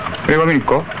All sounds in WAV format are spoken by Yusaku himself (Also Andy Garcia and Michael Douglas).
Dialogue from TV ad for men's hair styling lotion.